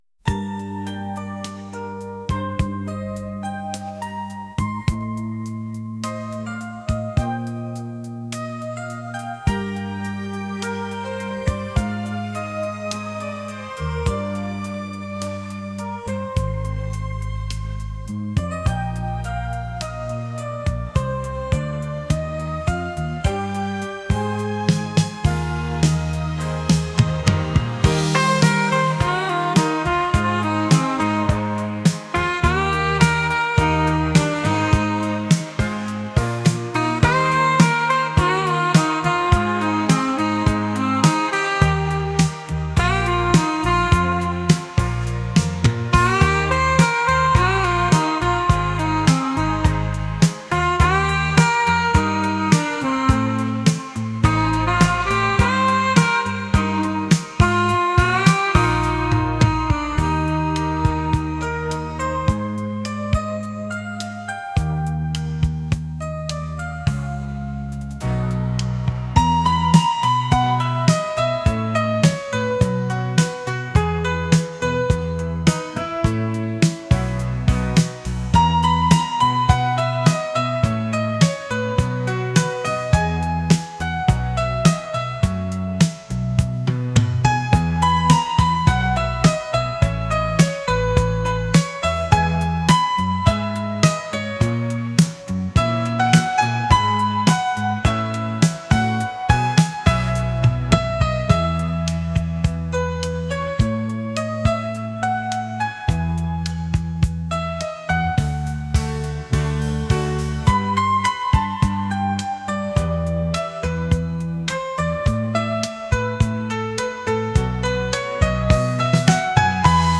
pop | smooth